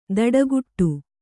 ♪ daḍaguṭṭu